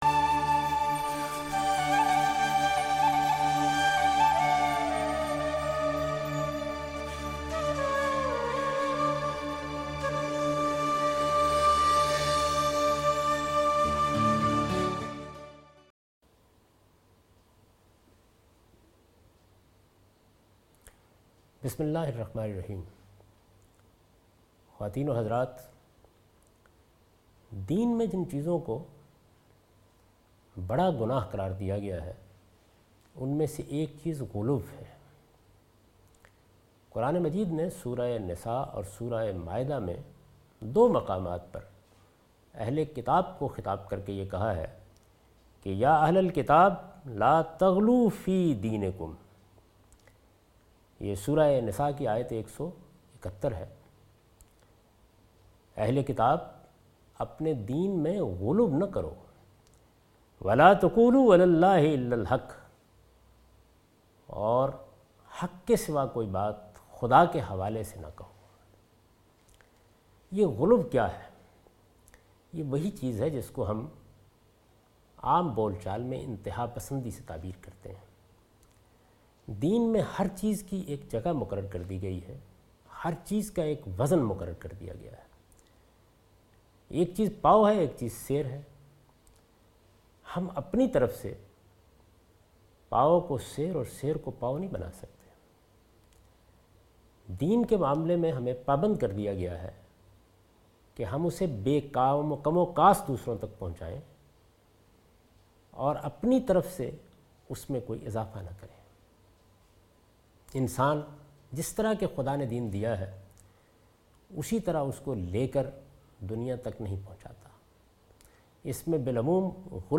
This series contains the lecture of Javed Ahmed Ghamidi delivered in Ramzan. He chose 30 different places from Quran to spread the message of Quran. In this lecture he discuss the sin of spreading lies in the name or religion and God.